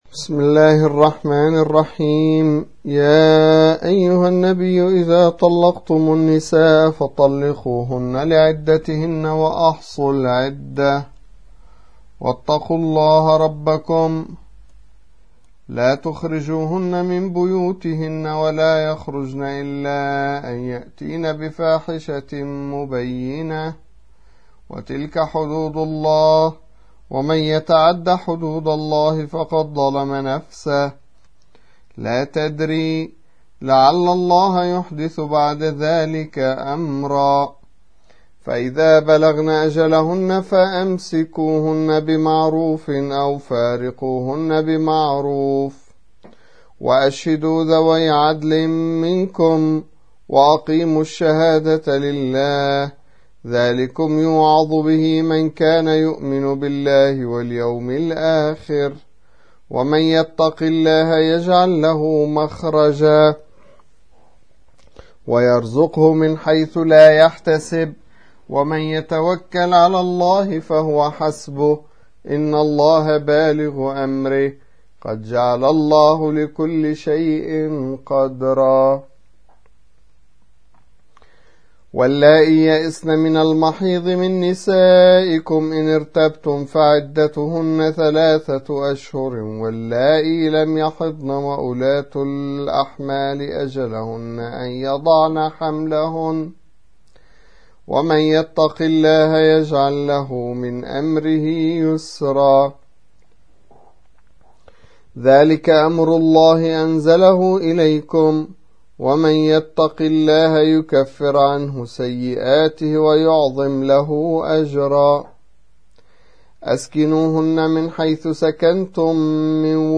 65. سورة الطلاق / القارئ